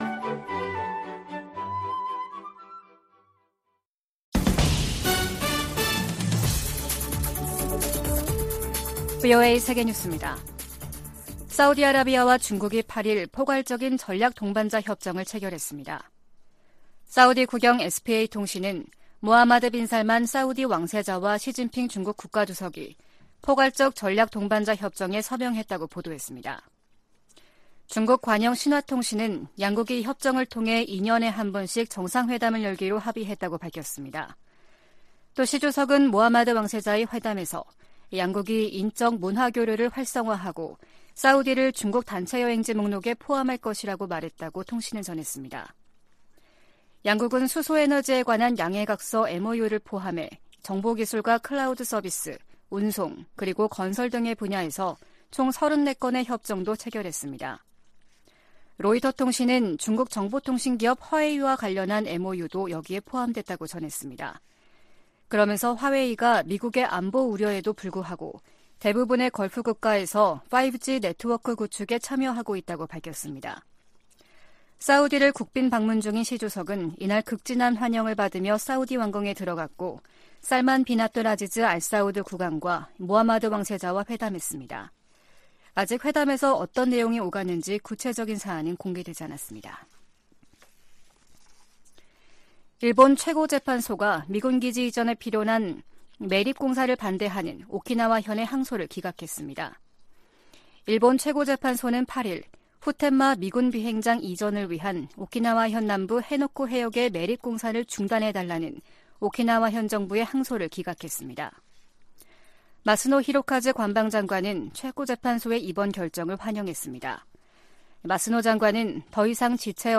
VOA 한국어 아침 뉴스 프로그램 '워싱턴 뉴스 광장' 2022년 12월 9일 방송입니다. 미국은 북한 정권의 계속된 도발에 대응해 한국, 일본 등과 연합 훈련과 협력을 계속할 것이라고 백악관 고위관리가 밝혔습니다. 오는 12～13일 인도네시아 자카르타에서 미한, 한일, 미한일 북 핵 수석대표 협의가 열립니다.